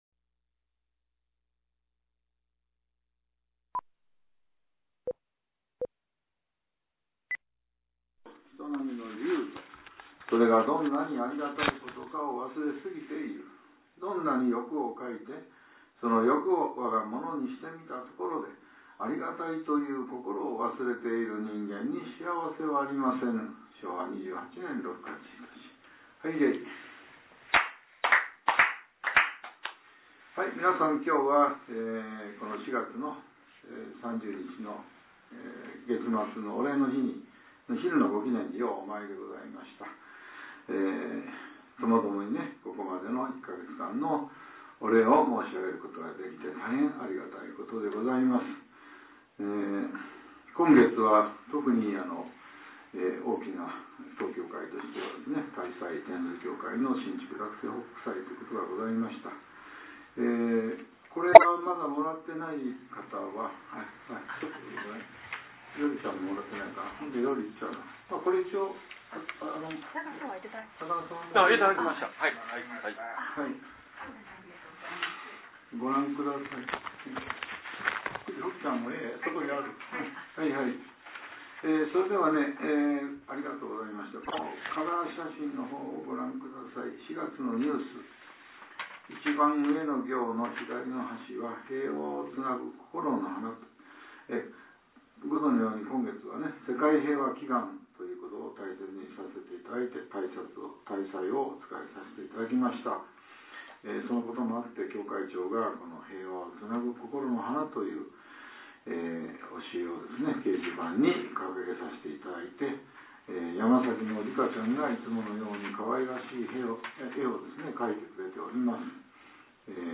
昼ご祈念後（音声ブログ） | 悩み相談・願い事祈願「こころの宮」